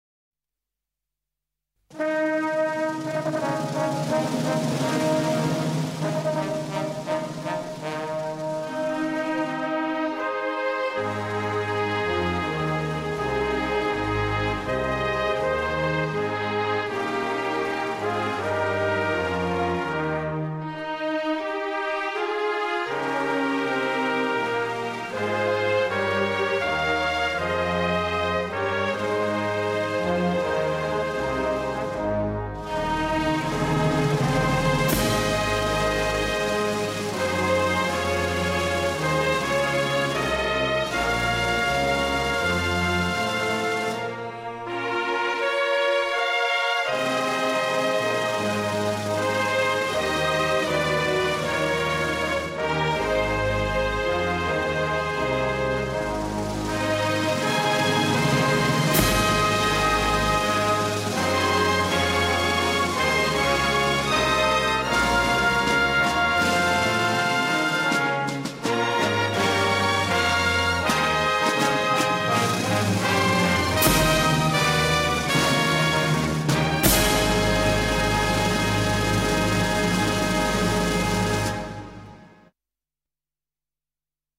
Negaraku_instrumental.mp3